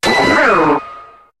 Cri de Lançargot dans Pokémon HOME.